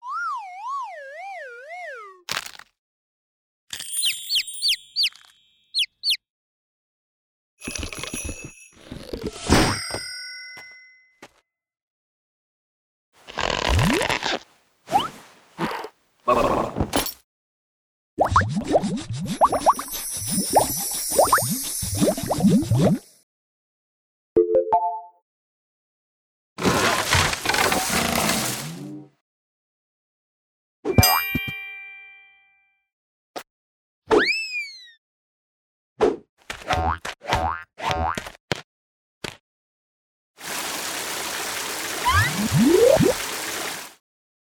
游戏音效[卡通] – 深圳声之浪潮文化传播有限公司
卡通音效的设计需要捕捉到卡通作品中夸张、诙谐且有趣的特质，使其音效与画面相得益彰。不仅要有基础的环境音效，还需要在此基础上加入富有创意的声音设计，以增强角色和情节的表现力。